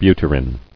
[bu·ty·rin]